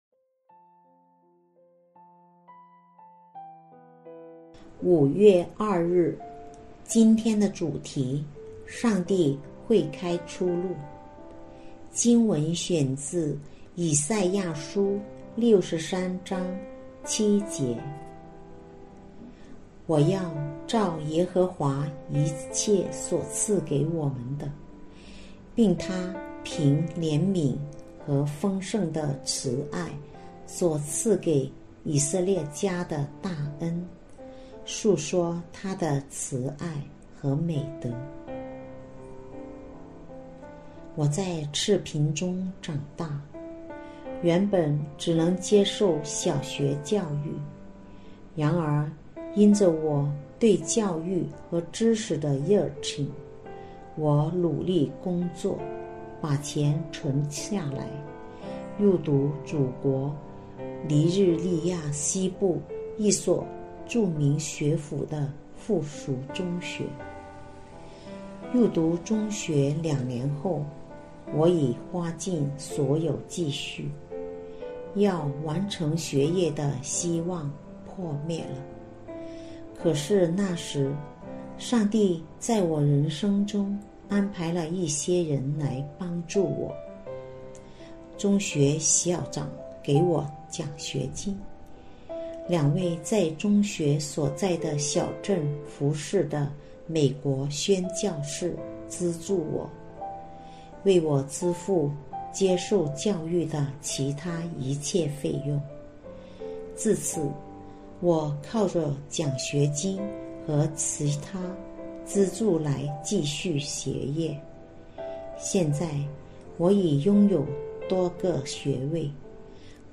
循道衞理聯合教會香港堂 · 錄音佈道組 Methodist Outreach Programme
錄音員